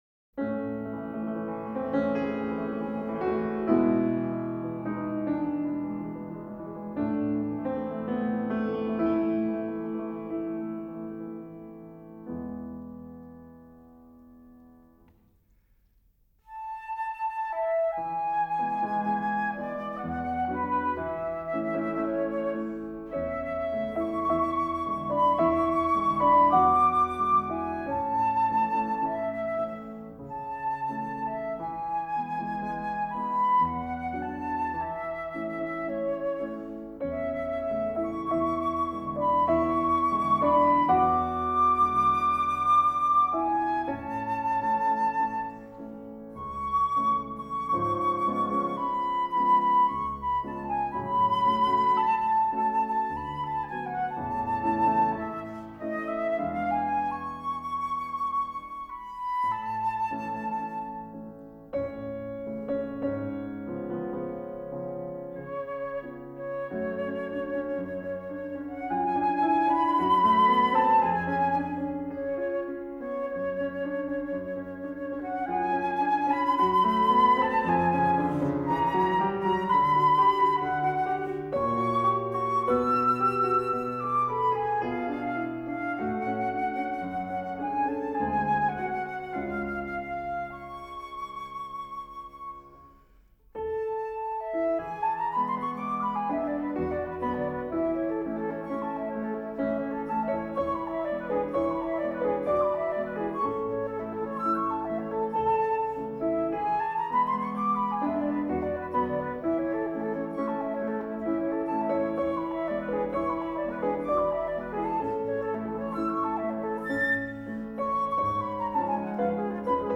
flauto
pianoforte
Musica Classica / Cameristica